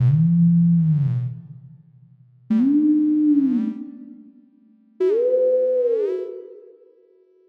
Cry_01_Projekt.mp3